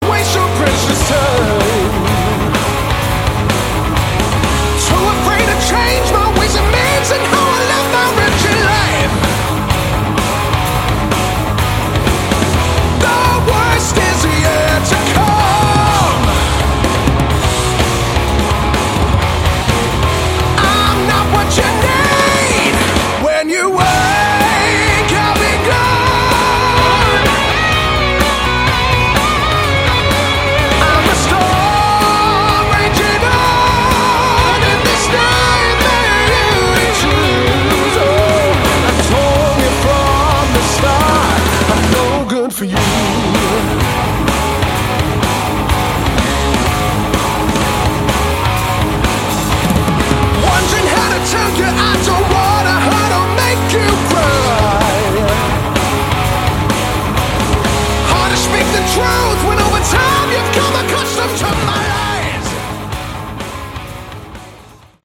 Category: Hard Rock
vocals
lead guitar
bass
drums